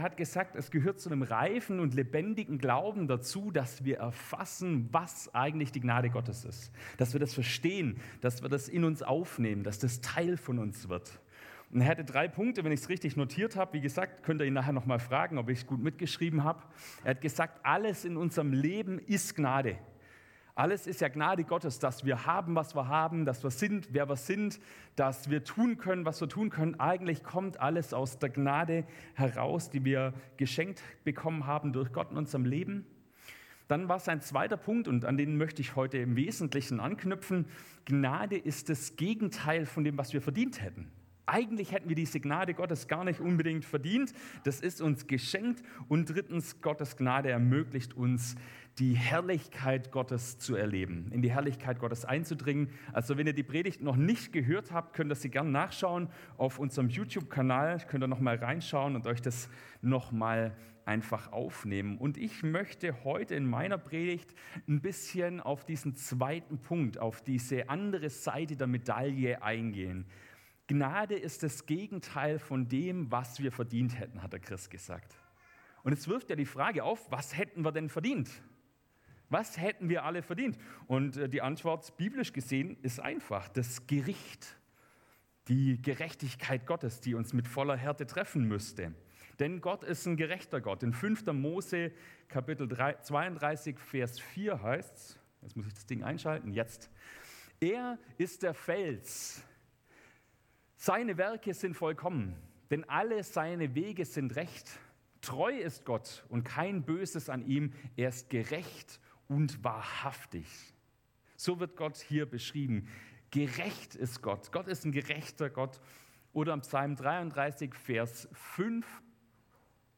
Gottesdienst am 09.06.2024